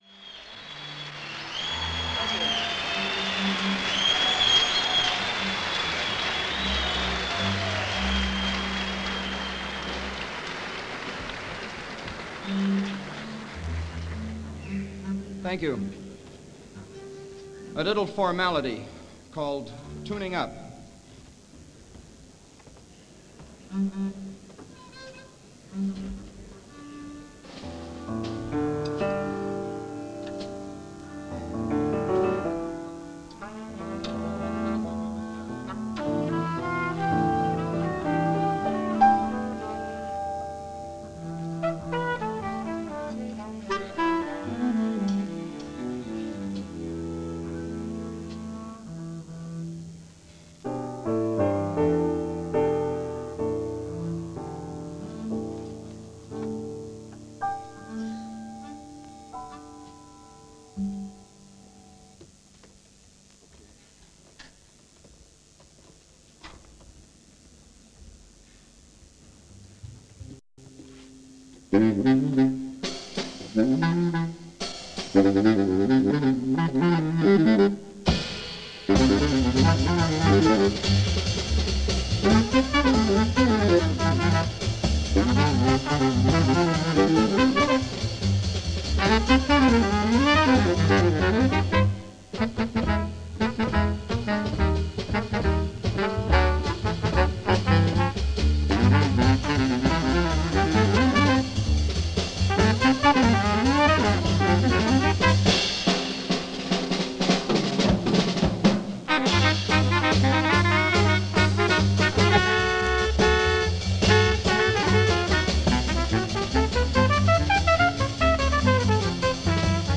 trumpet
drums